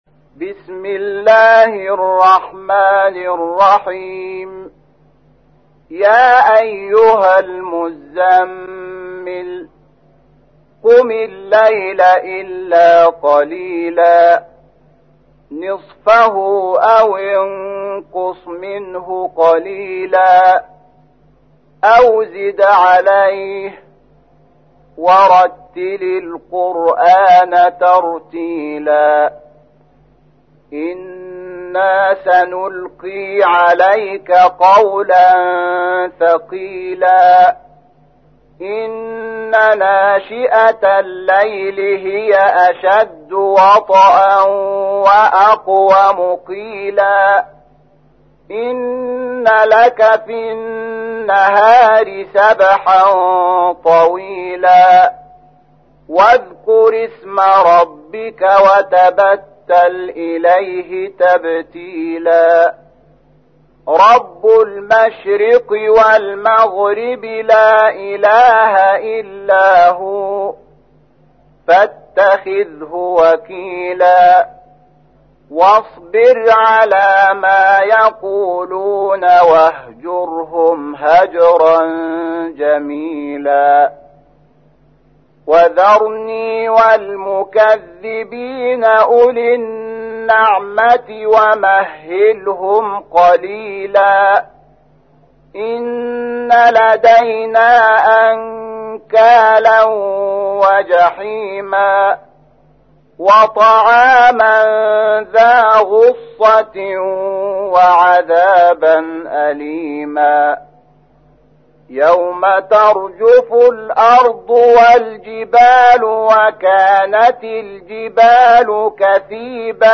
تحميل : 73. سورة المزمل / القارئ شحات محمد انور / القرآن الكريم / موقع يا حسين